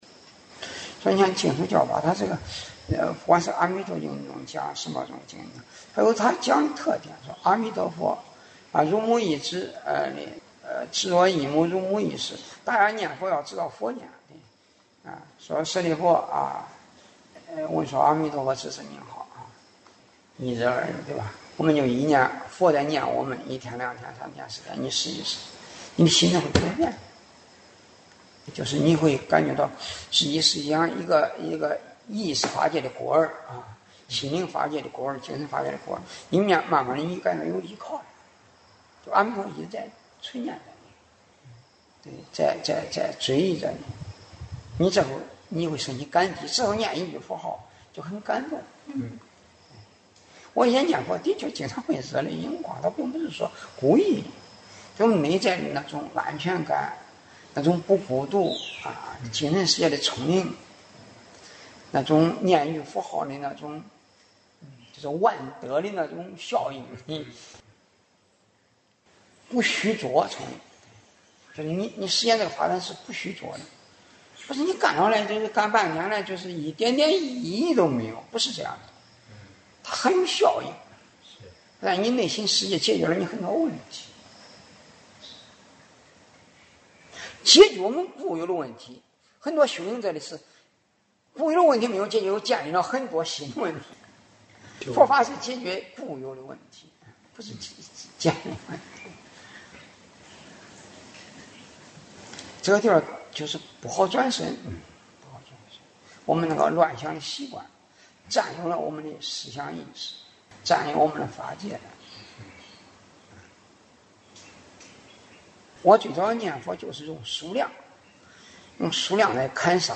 课后开示